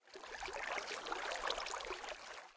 water.ogg